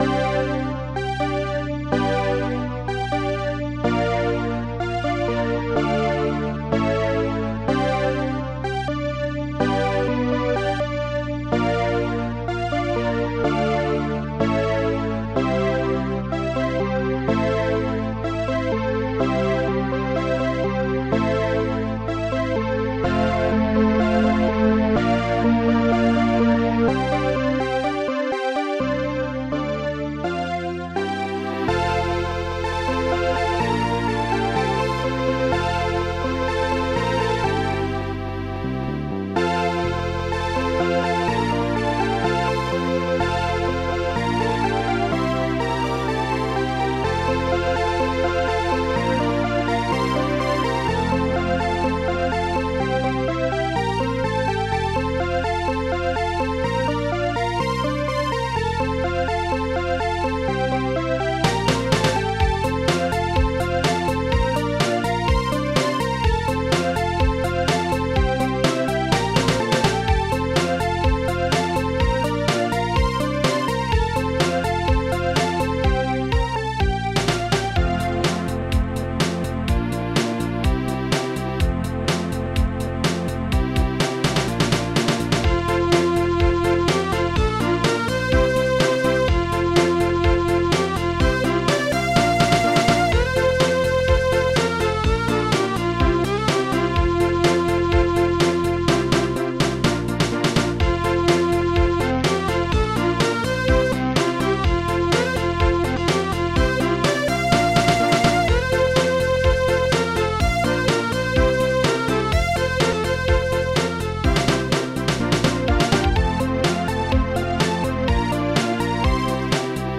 Protracker and family
is sort of cute